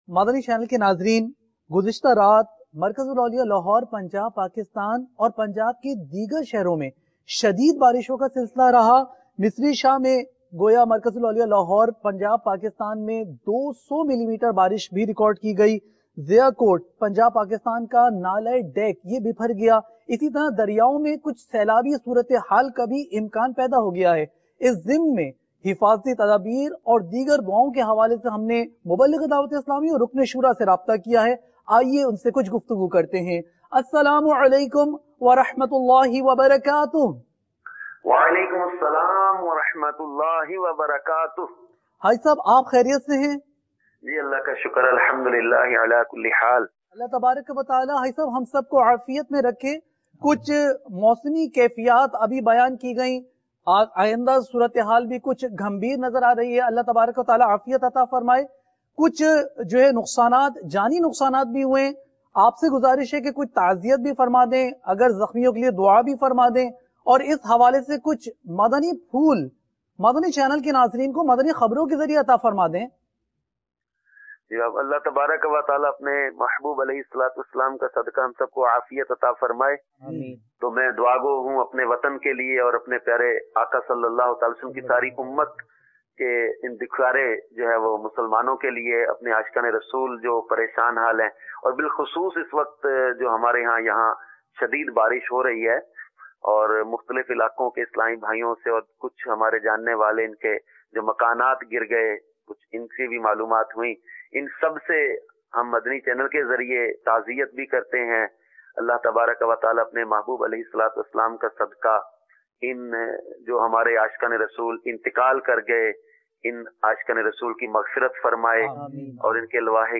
News Clip
Live call